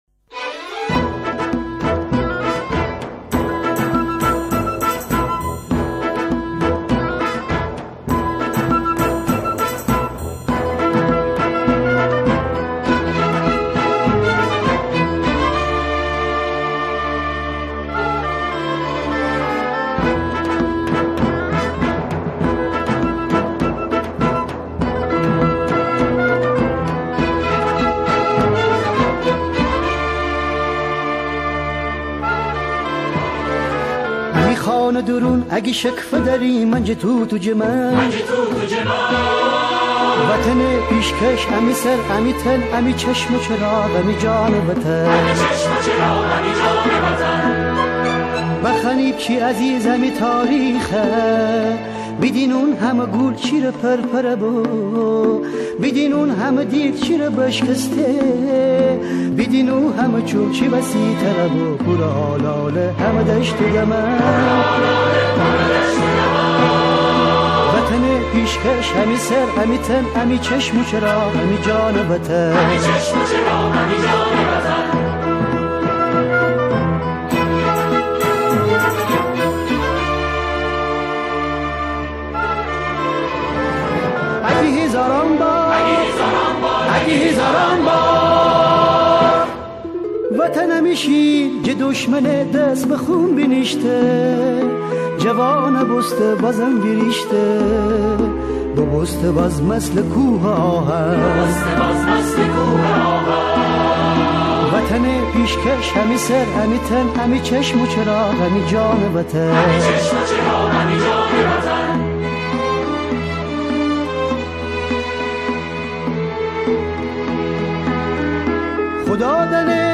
گروهی از همخوانان